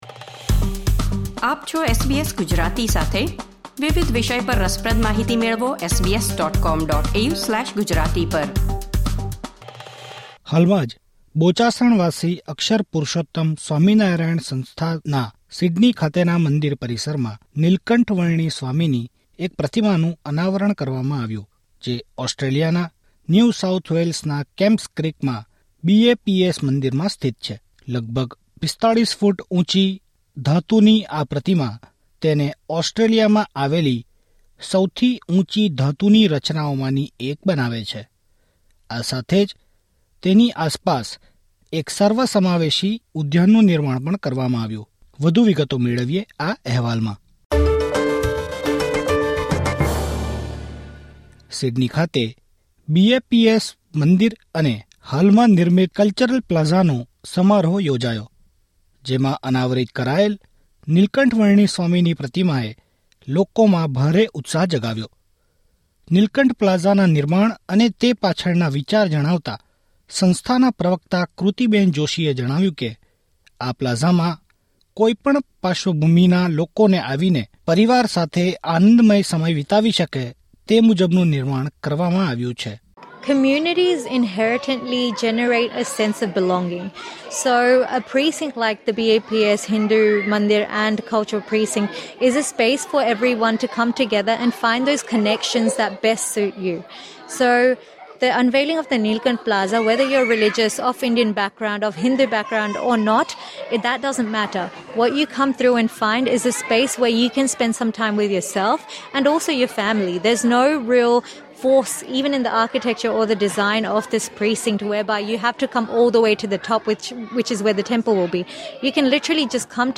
પ્રતિમાના અનાવરણ પ્રસંગને વર્ણવતો અહેવાલ મેળવો.